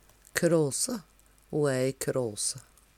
kråse - Numedalsmål (en-US)